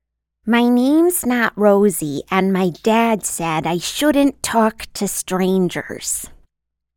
动画角色-小孩